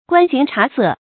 观形察色 guān xíng chá sè 成语解释 见“观貌察色”。
成语繁体 觀形詧色 成语简拼 gxcs 成语注音 ㄍㄨㄢ ㄒㄧㄥˊ ㄔㄚˊ ㄙㄜˋ 常用程度 一般成语 感情色彩 中性成语 成语用法 作谓语、宾语、定语；用于处世 成语结构 联合式成语 产生年代 古代成语 近 义 词 观貌察色 、观颜察色 成语例子 且术士喜言怪诞……并造出无数捕风捉影之说，观形察色之机，以肥囊利己。